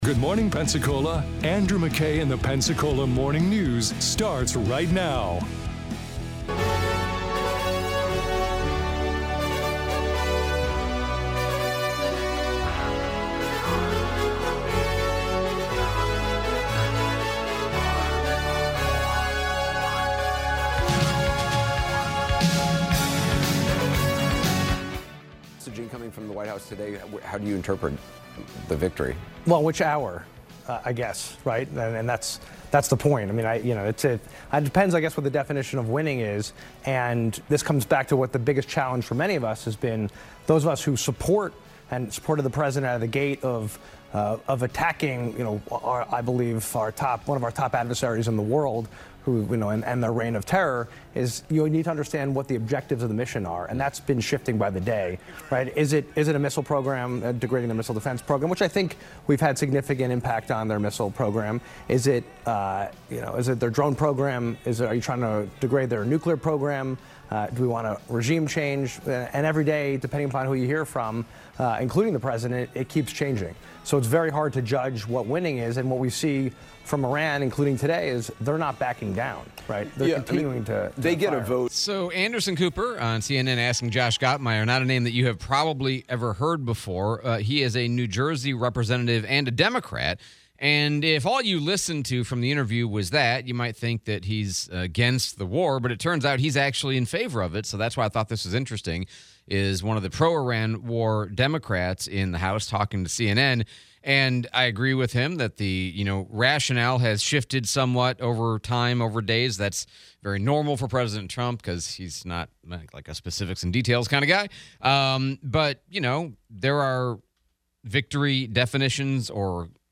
Iran War, replay of US Senator Scott Interview